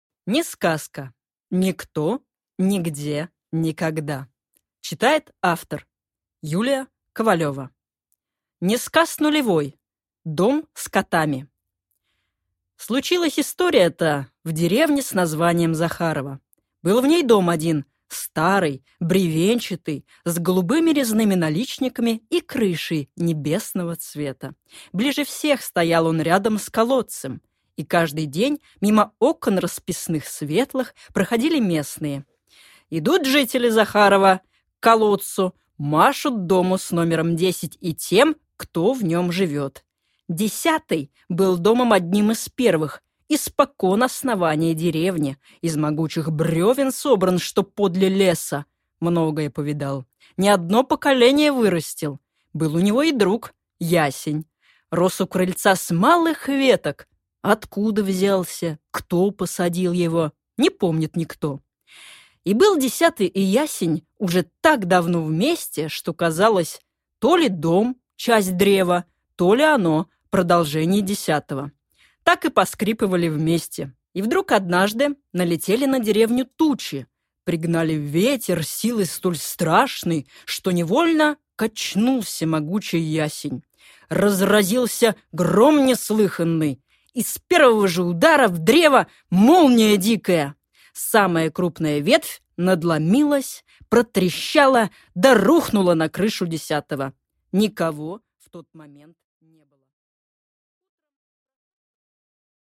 Аудиокнига НеСказка. Никто. Нигде. Никогда | Библиотека аудиокниг